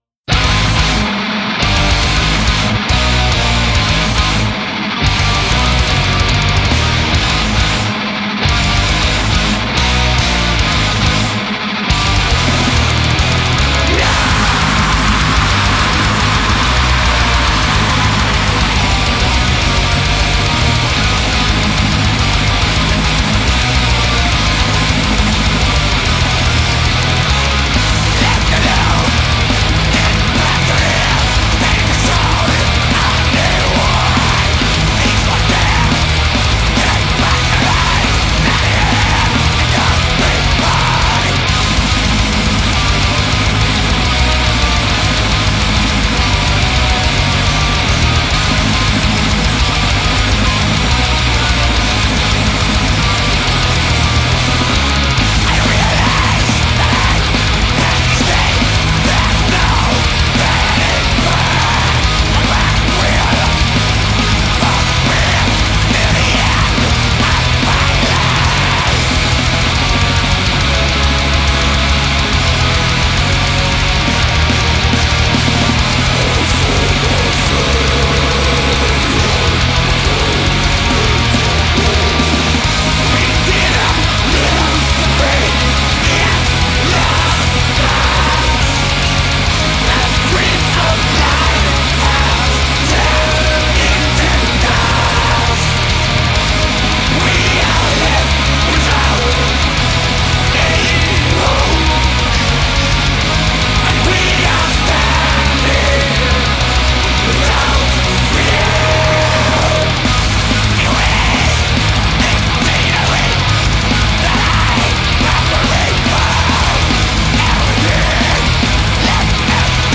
Death Metal